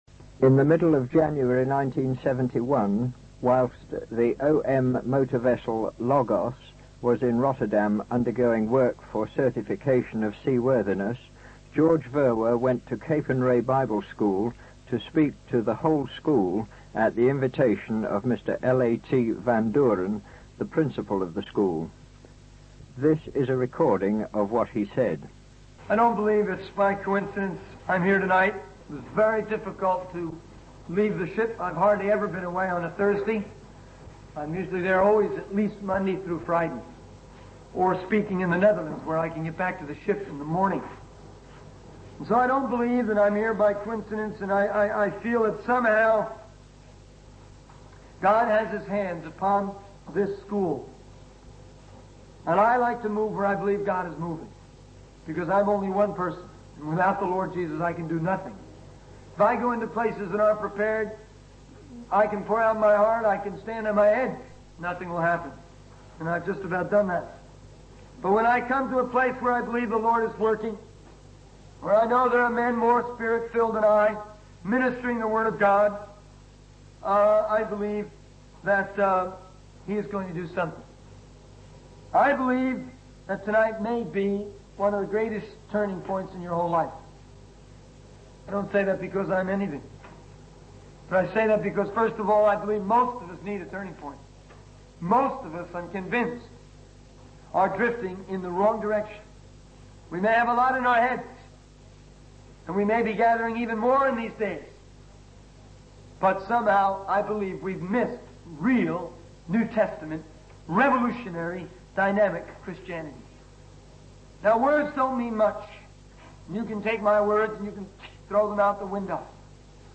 In this sermon, the preacher emphasizes the need for individuals to become revolutionists for Christ. He highlights that Christ knows our weaknesses and accepts us regardless of our state.